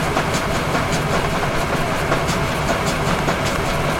Conveyor Belt
A steady conveyor belt running with rhythmic roller clicks and motor hum
conveyor-belt.mp3